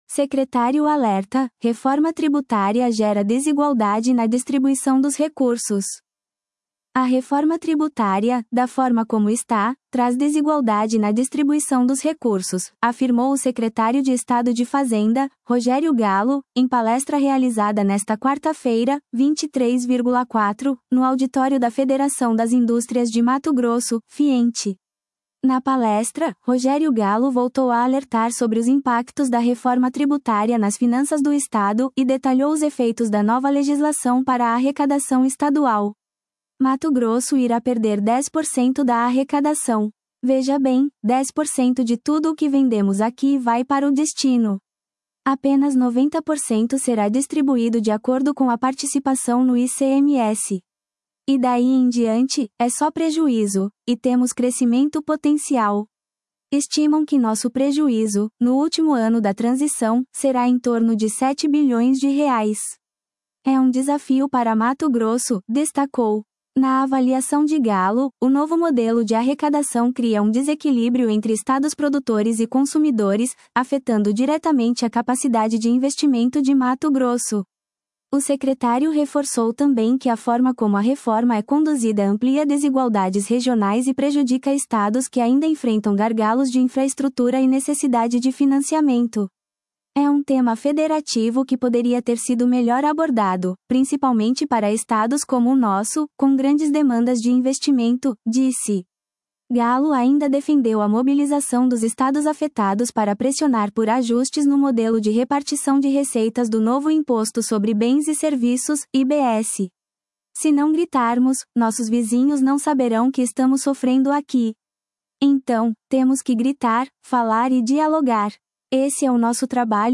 “A Reforma Tributária, da forma como está, traz desigualdade na distribuição dos recursos”, afirmou o secretário de Estado de Fazenda, Rogério Gallo, em palestra realizada nesta quarta-feira (23.4), no auditório da Federação das Indústrias de Mato Grosso (Fiemt).